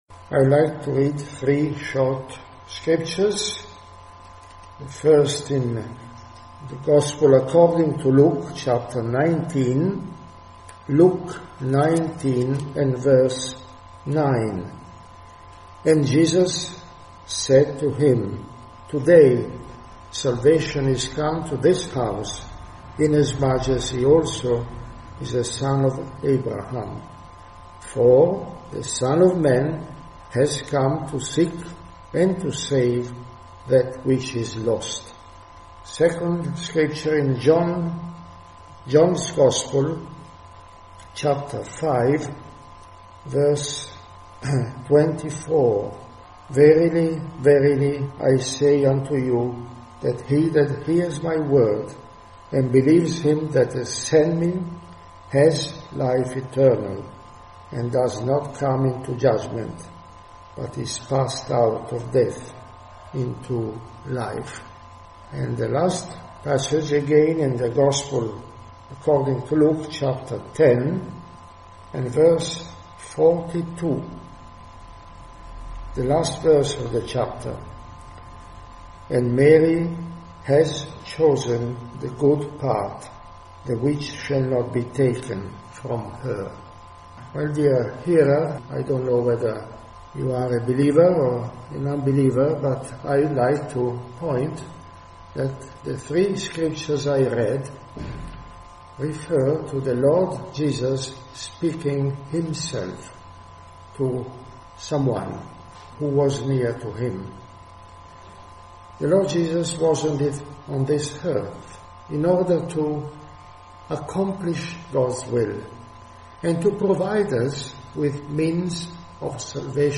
Gospel Broadcasts